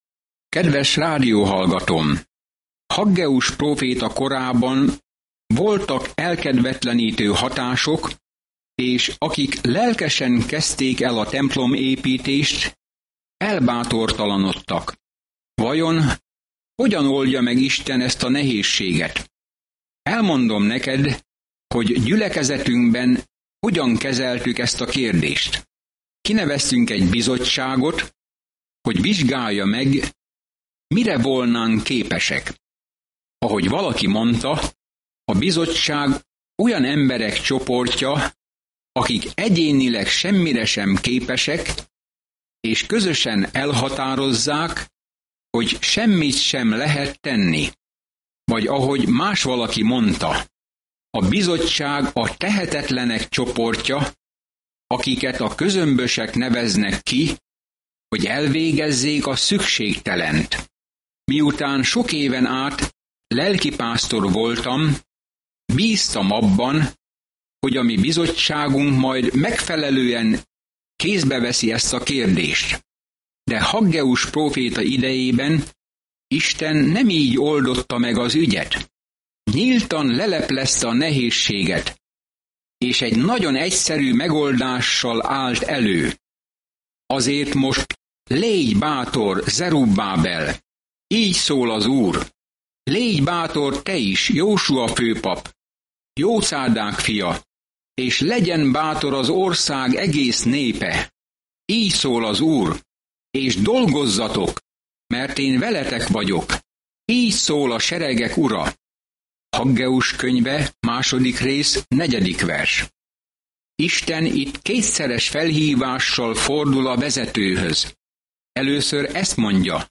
Szentírás Haggeus 2:4 Nap 4 Olvasóterv elkezdése Nap 6 A tervről Aggeus „csináld kész” hozzáállása arra készteti a zavarodott Izraelt, hogy építsék újjá a templomot, miután visszatérnek a fogságból. Napi utazás Haggaiban, miközben hallgatja a hangos tanulmányt, és olvassa el Isten szavának kiválasztott verseit.